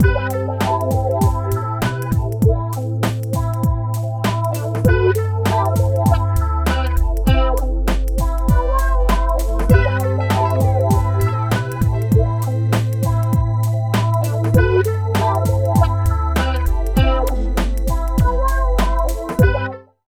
70 LOOP   -L.wav